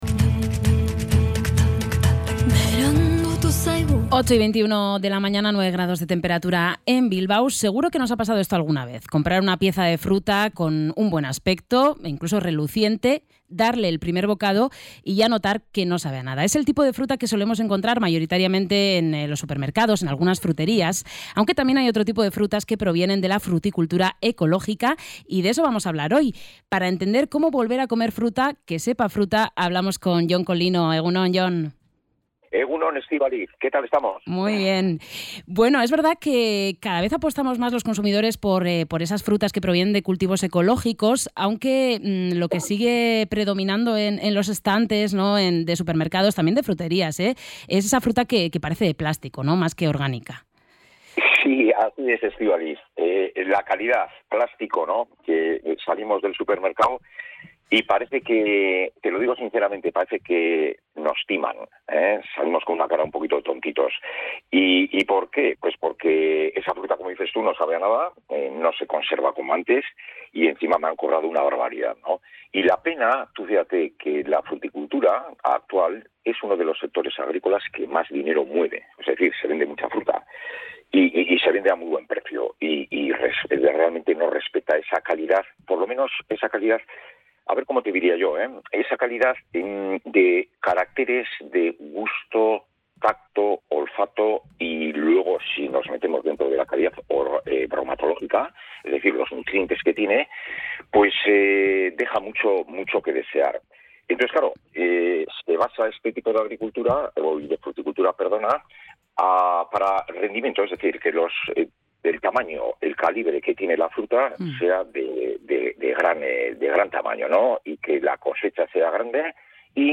Sobre este fenómeno y las alternativas que ofrece la agricultura ecológica conversamos hoy